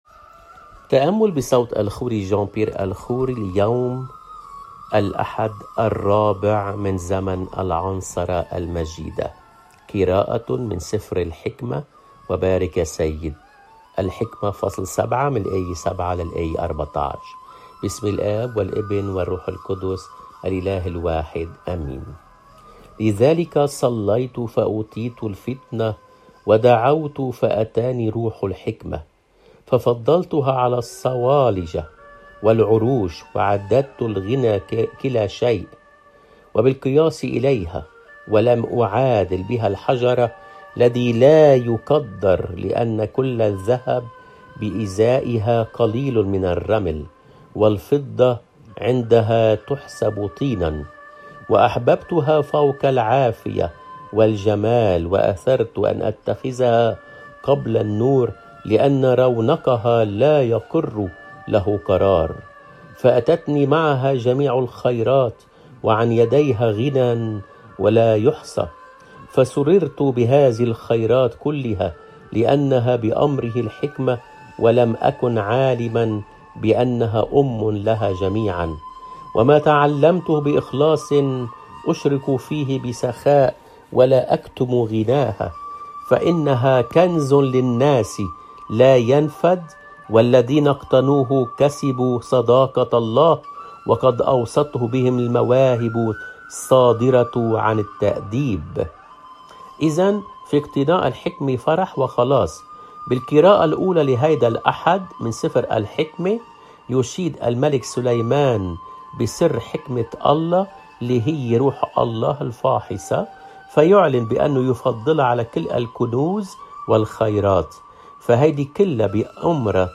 قراءة من العهد القديم